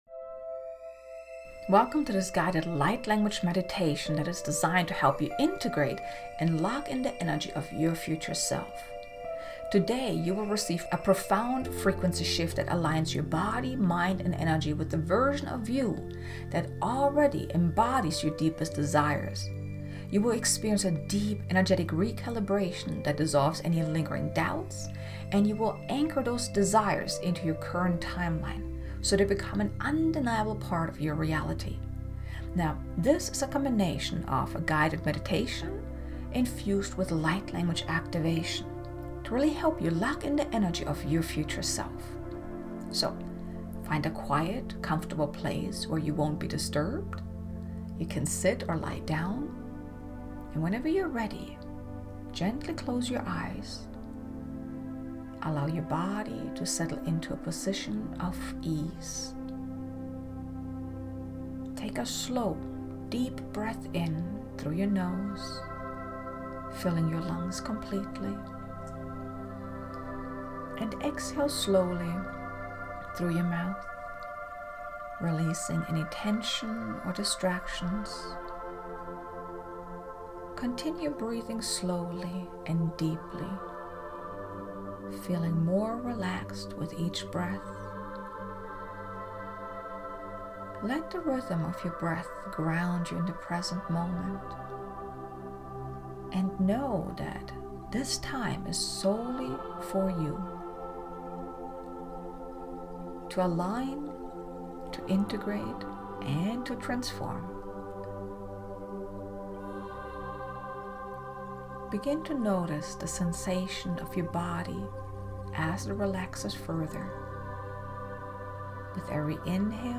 Guided-Meditation-Locking-in-the-Energy-of-Your-Future-Self.mp3